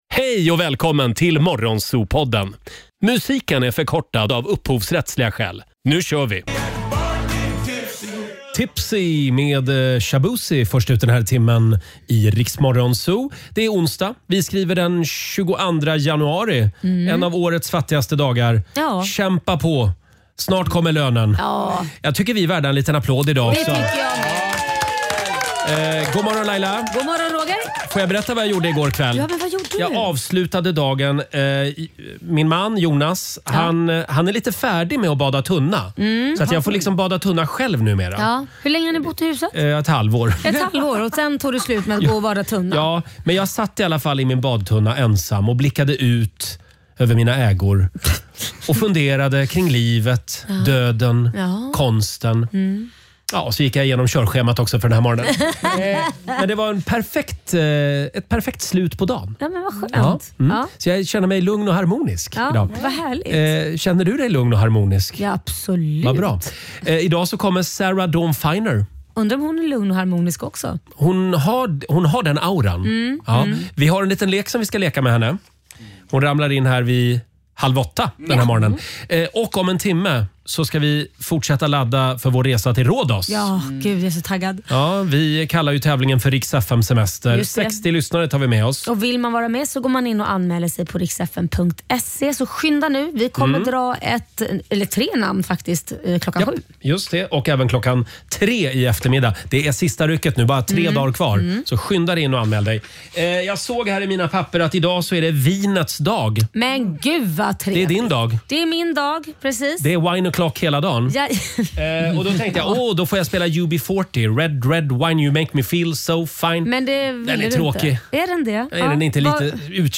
Vi gästas även av multitalangen Sarah Dawn Finer som bjuder spännande detaljer om sitt liv och i Familjerådet pratas det om gravid-cravings.